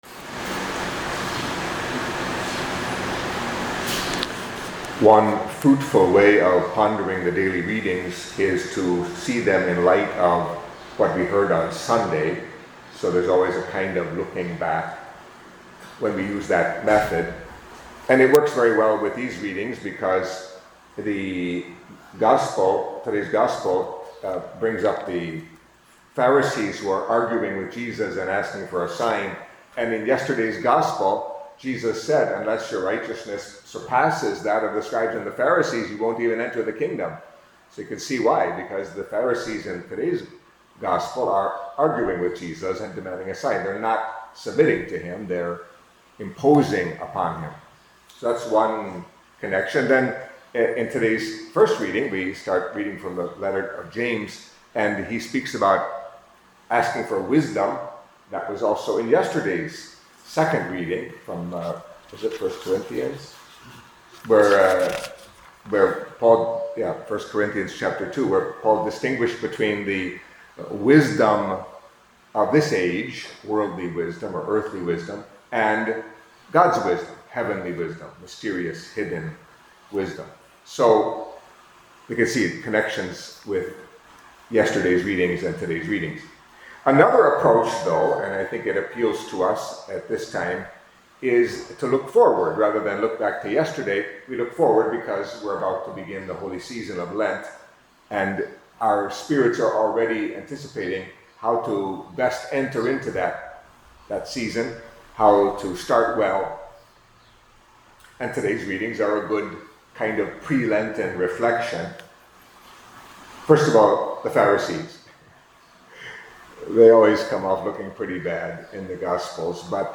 Catholic Mass homily for Monday of the Sixth Week in Ordinary Time